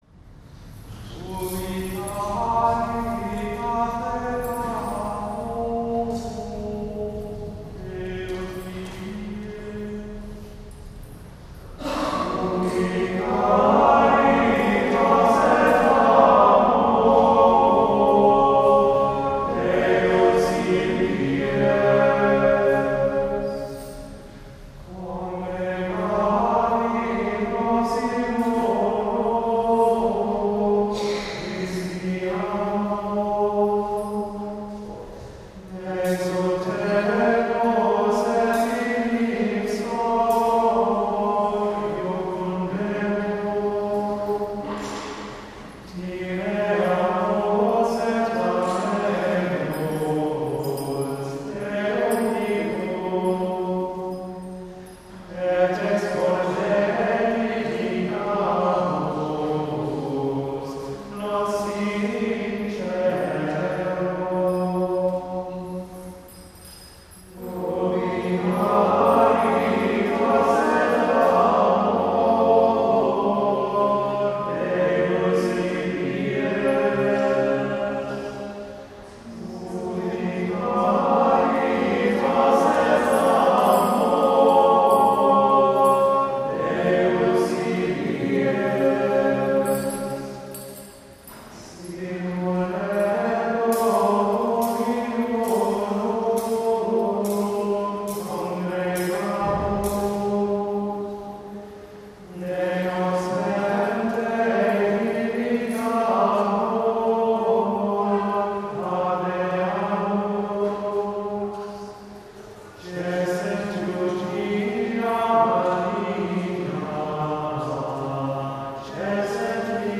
The following recording was made of the Schola Cantorum singing the ancient Latin antiphon, Ubi Caritas, during Offertory at an October Sunday Missa Cantata. Members of the choir created a four-part harmony for the response.
The Schola sings in the stalls and are vested in cassock and surplice.